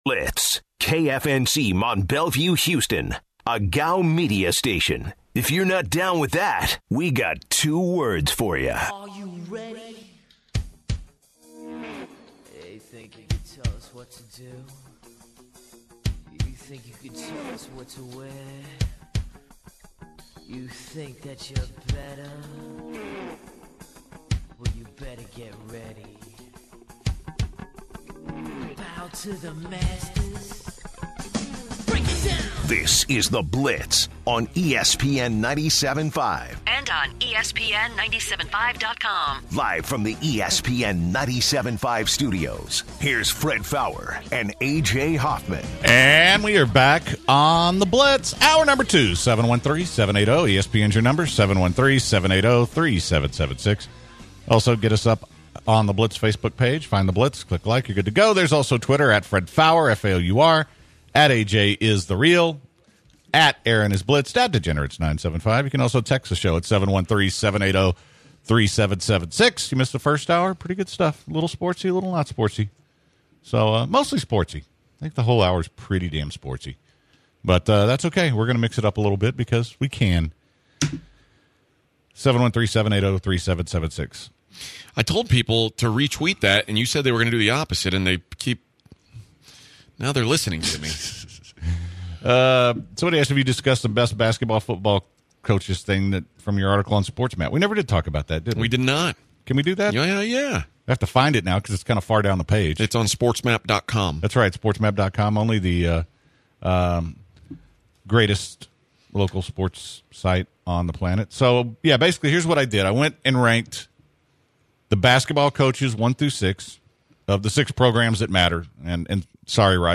On the second hour, the guys talk about the best NCAA basketball and football coaches in Texas, they also talk to comedian Tommy Davidson about his career and his time on the show In Living Color as well.